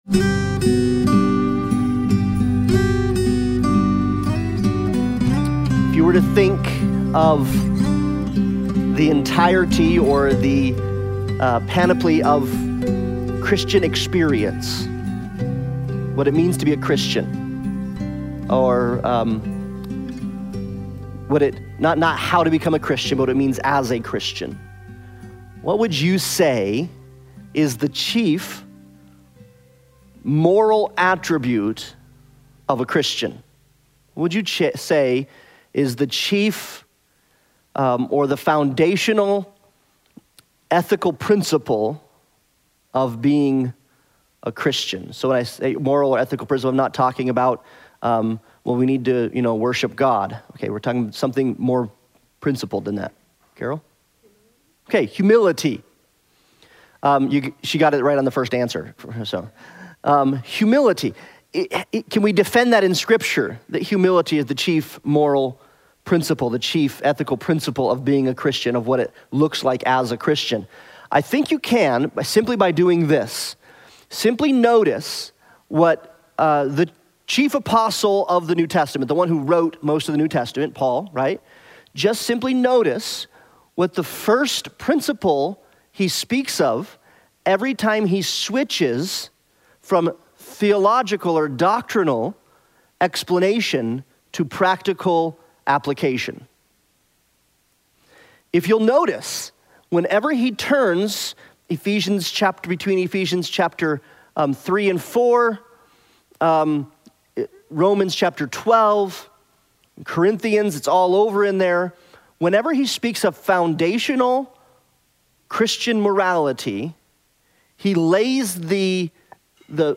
Romans 12:3-5 Service Type: Sunday Bible Study « Fragile People